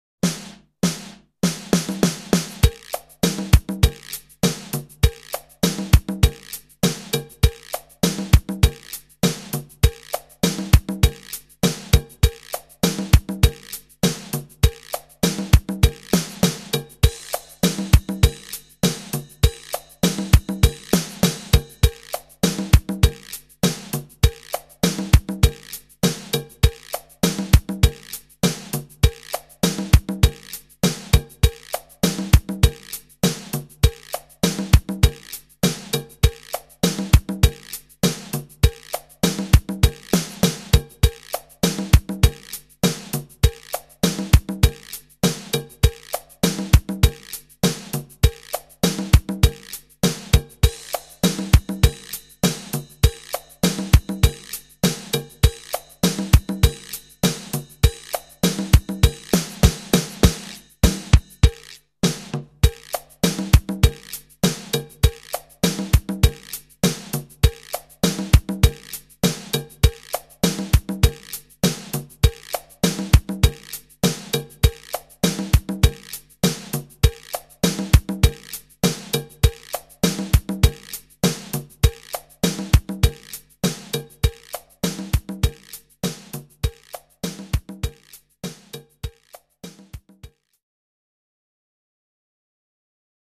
Teaching Track - (Chacha)